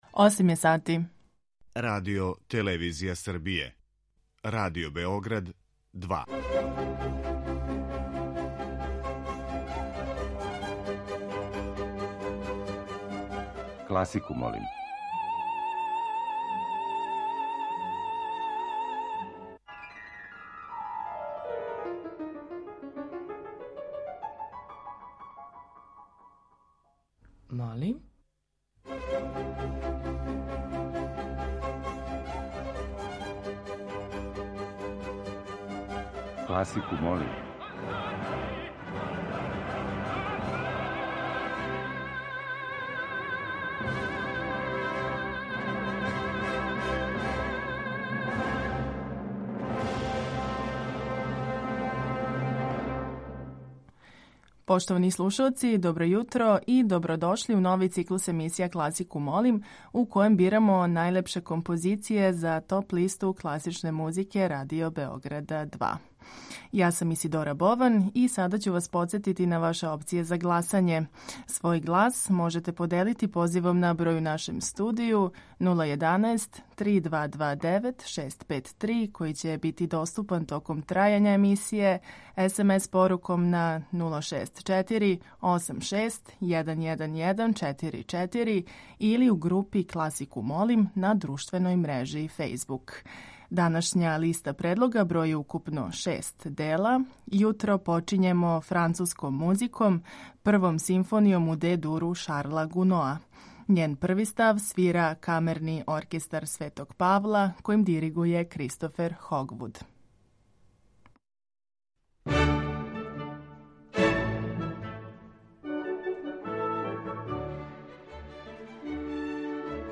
Барокне свите